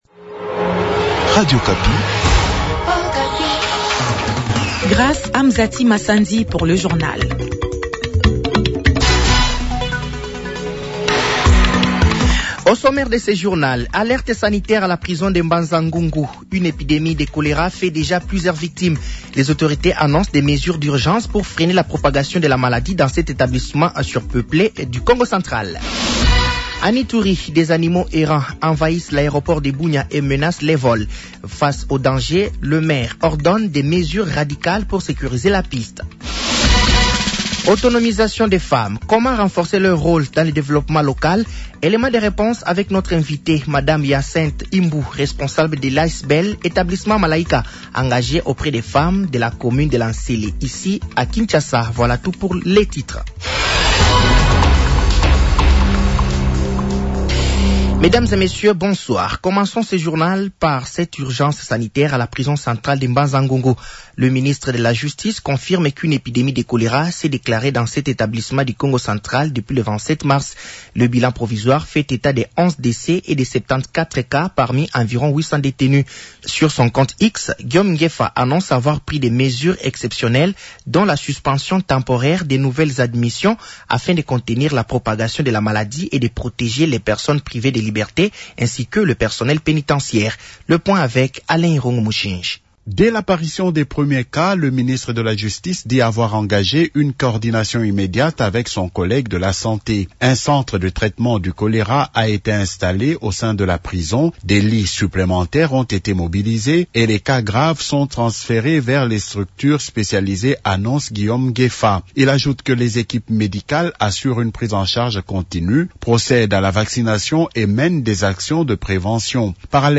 Journal français de 18h de ce lundi 30 mars 2026